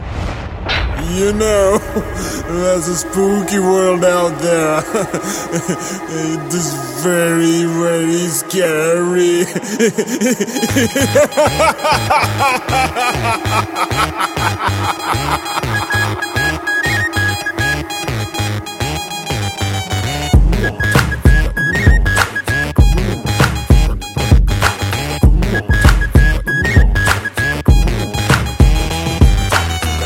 CategoryTamil Ringtones